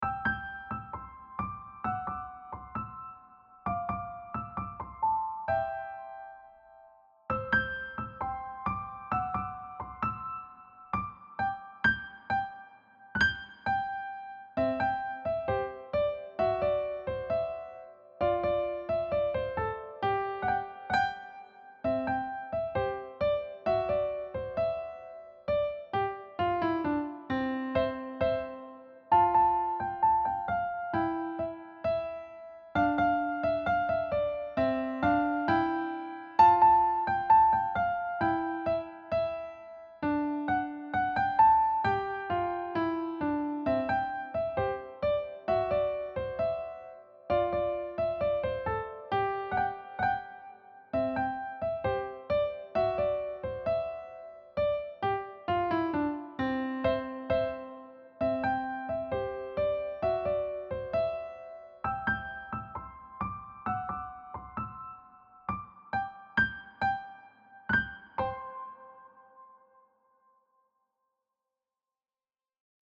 Key: C position with chromatic left-hand patterns
Time Signature: 4/4